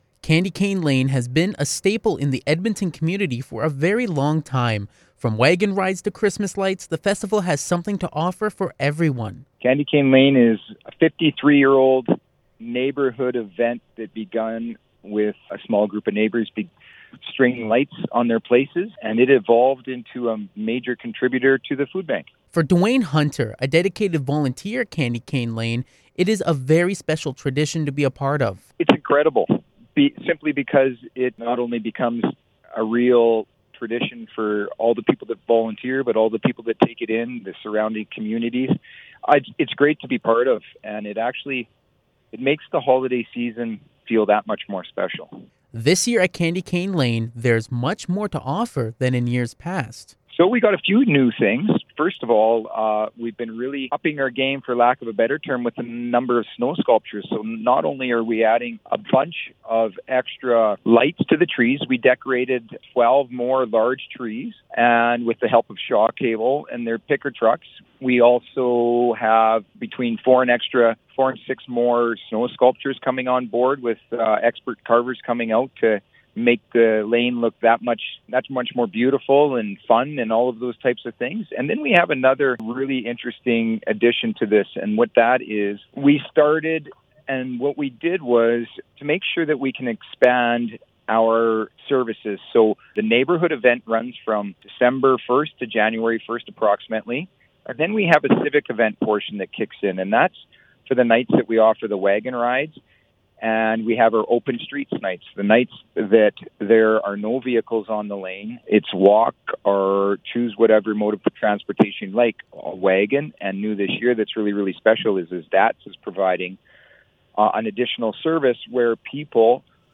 Candy-Cane-Lane-Interview-CanadaInfo.mp3